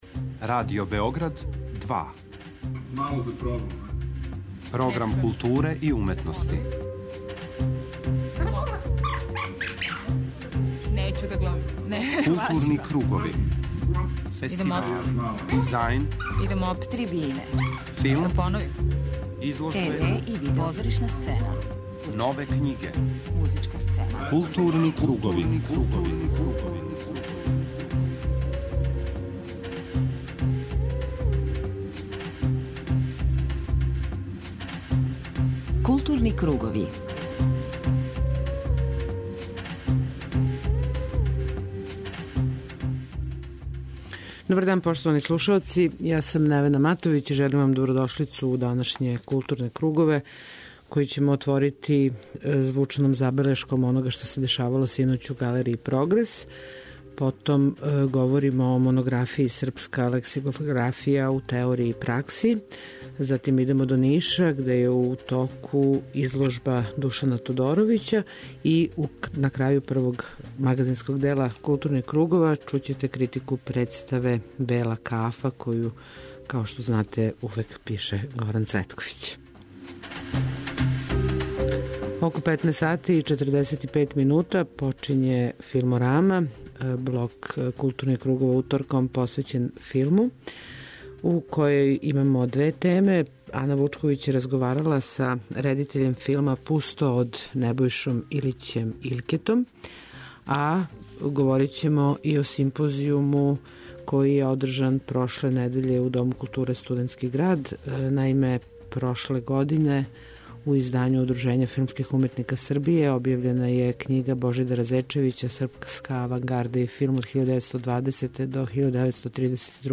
Данас у Филморами слушате звучне белешке са симпозијума Српска авангарда и алтернативни филм, који је одржан прошле недеље у ДКСГ-у.